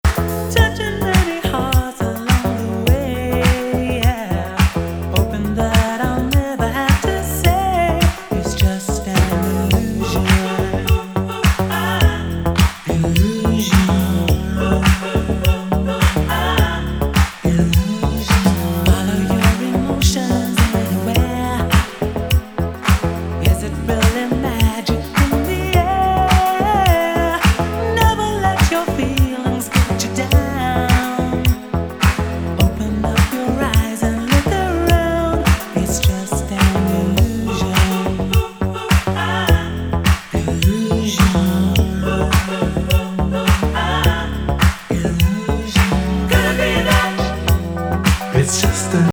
スムースなシンセサイザーにクラップ、ドラムのネットリイロっぽい質感にファルセット・コーラスが玉乱です。